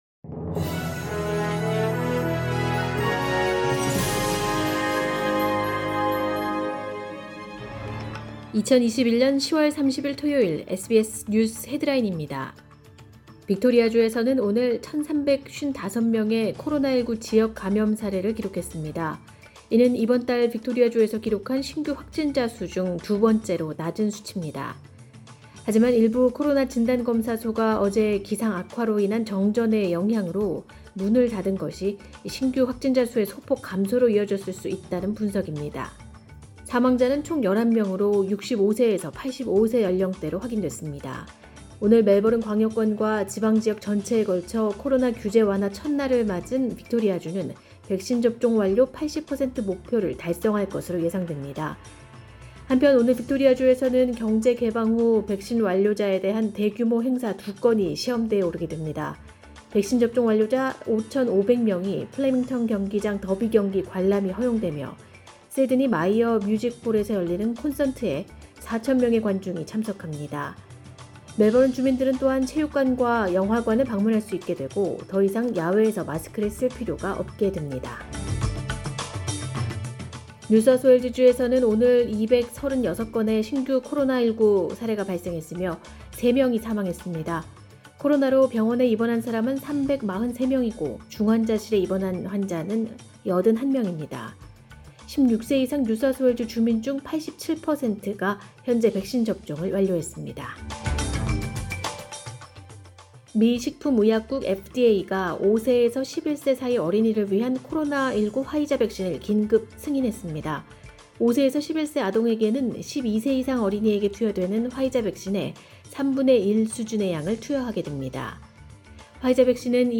2021년 10월 30일 토요일 SBS 뉴스 헤드라인입니다.